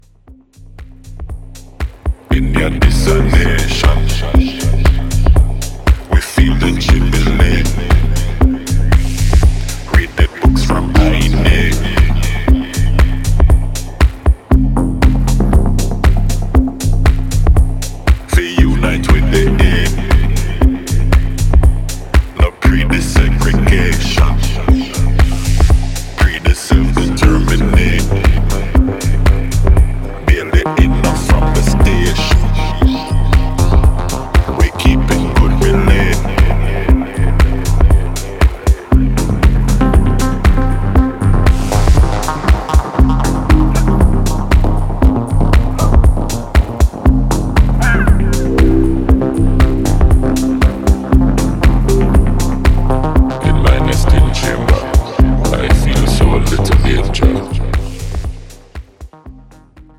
オーガニックな質感と緻密な音響設計が最高なダブ・テクノ作品。
New Release Dub Techno Techno